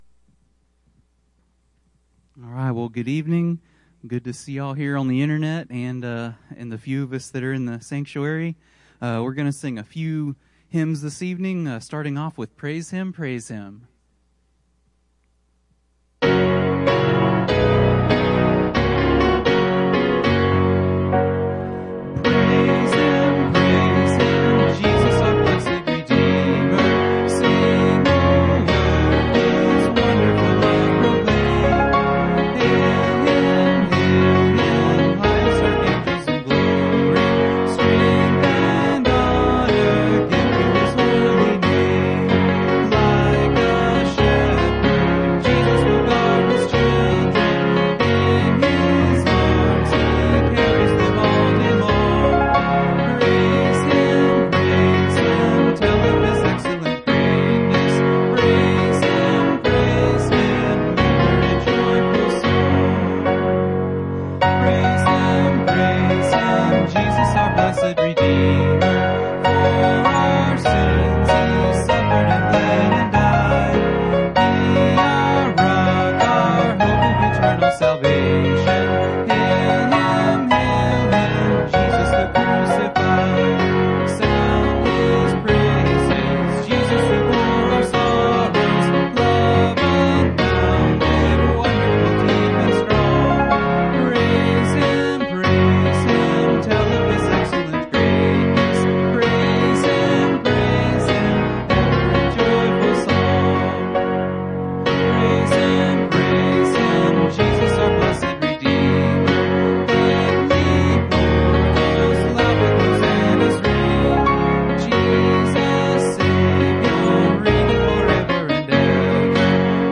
Bible Text: Exodus 11:1-10 | Preacher